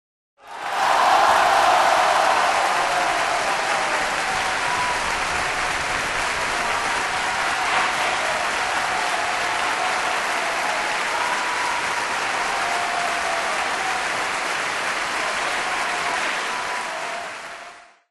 Crowd cheer sound effect